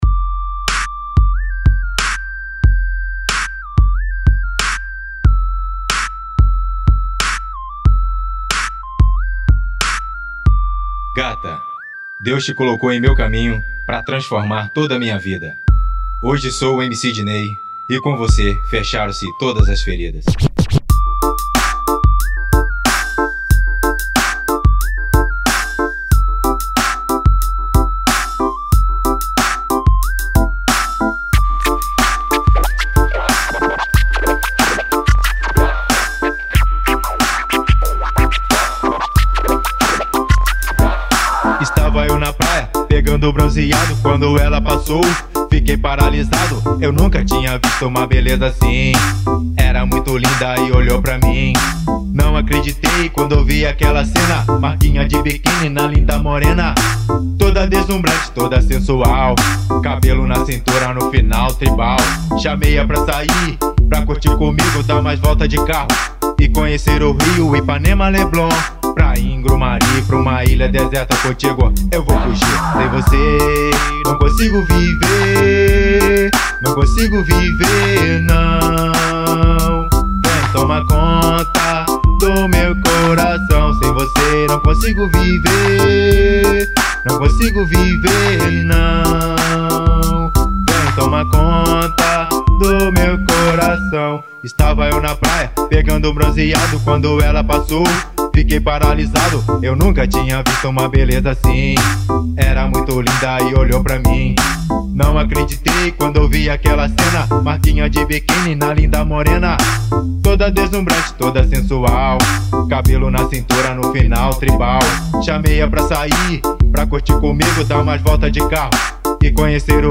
EstiloFunk